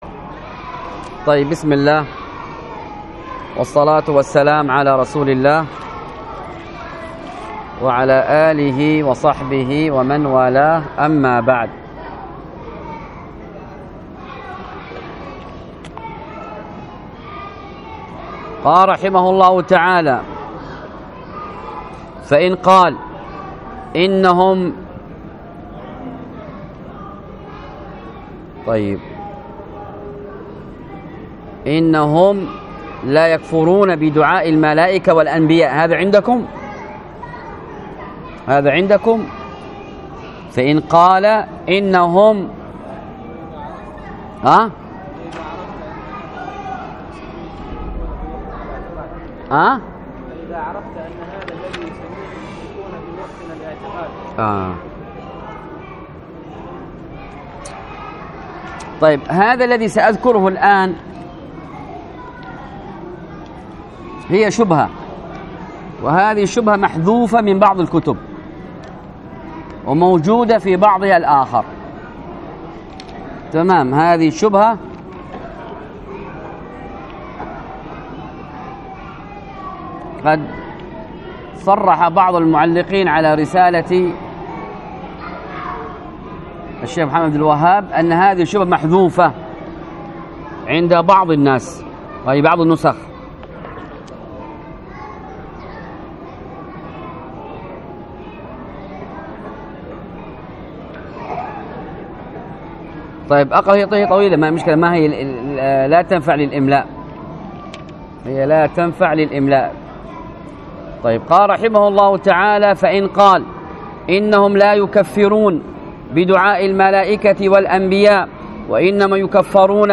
الدرس في كتاب الطهارة 2، ألقاها